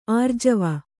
♪ ārjava